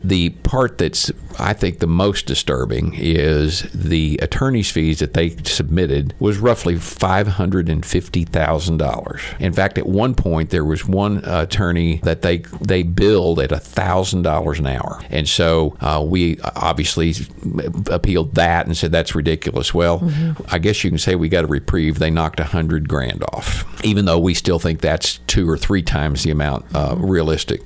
Ahead of the Baxter County Quorum Court meeting tonight, which will seek to appropriate fees associated with the ruling against the jail’s mail policy, Sheriff John Montgomery spoke with KTLO News to discuss the past and the future of the policy.
After losing the trial, Baxter County is required to pay over $102,000. Sheriff Montgomery says a lot of this cost comes from lawyer fees.